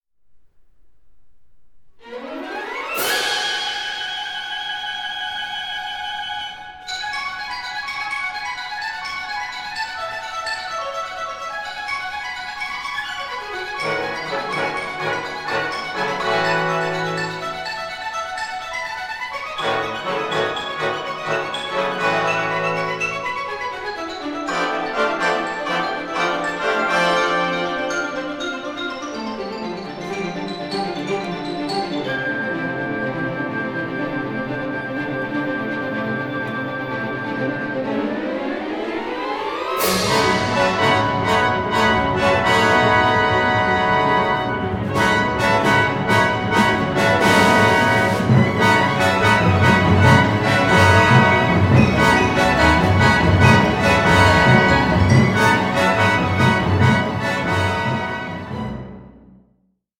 THE ULTIMATE AMERICAN “FOLK OPERA”
a live recording